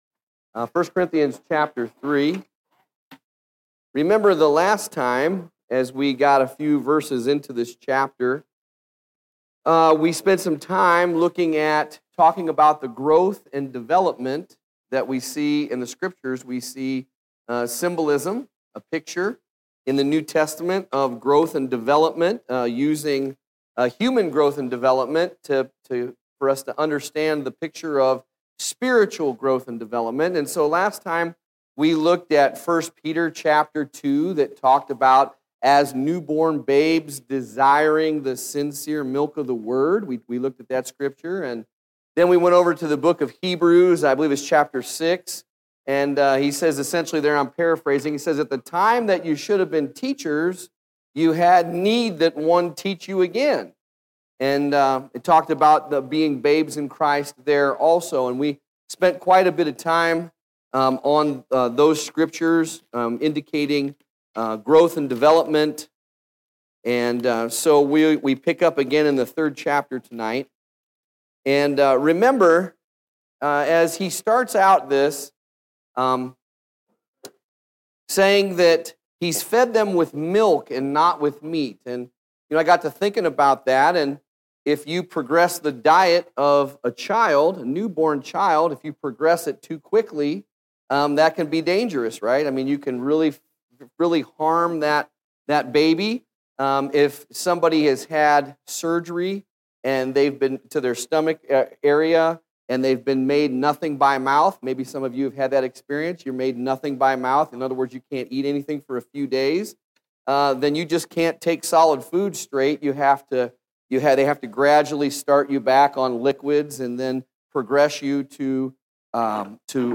Fairview Memorial Missionary Baptist 1 Corinthians Bible Study